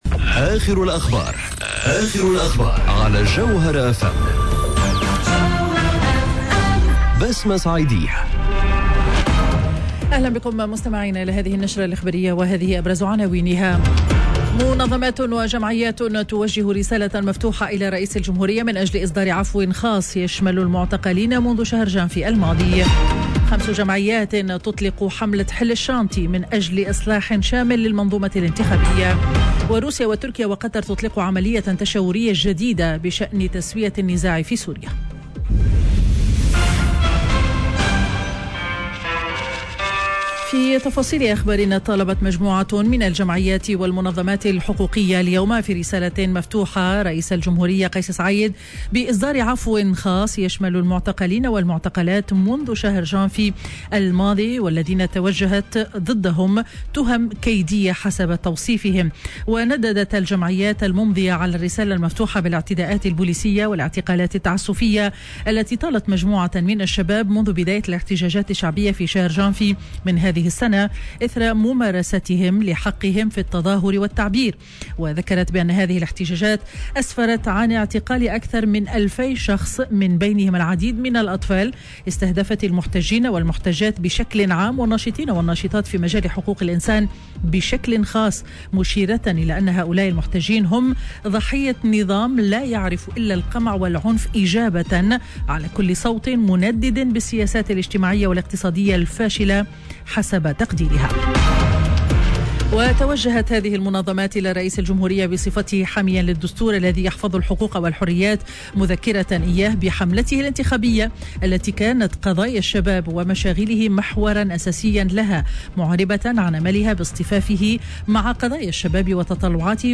نشرة أخبار منتصف النهار ليوم الخميس 11 مارس 2021